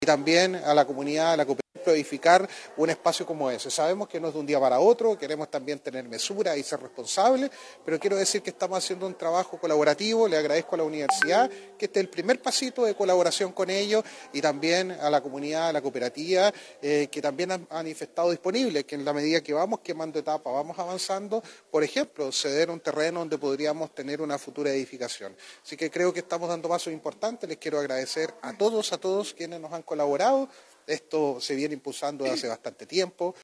La máxima autoridad comunal comentó que”